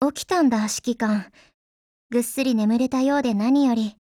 贡献 ） 协议：Copyright，其他分类： 分类:少女前线:SP9 、 分类:语音 您不可以覆盖此文件。